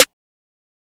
Dro Snare 1.wav